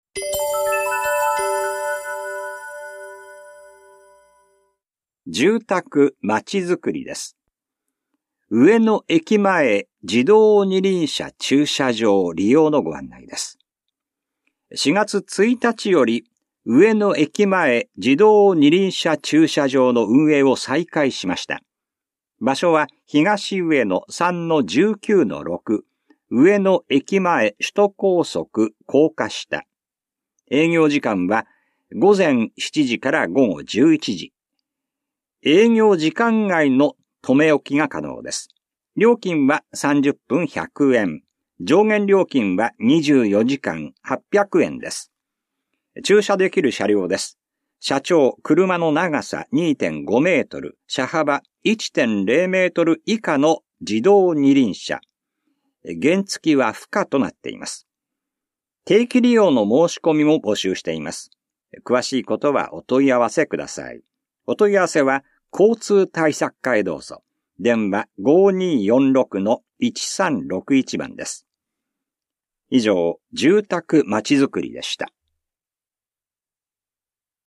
広報「たいとう」令和5年4月5日号の音声読み上げデータです。